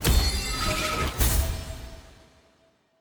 sfx-honor-vote-cast.ogg